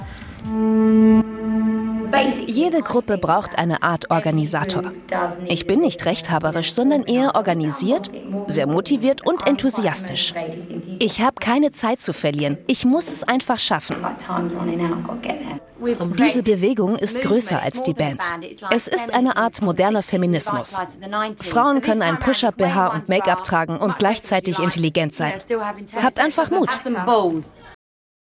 markant, sehr variabel, hell, fein, zart
Jung (18-30)
Doku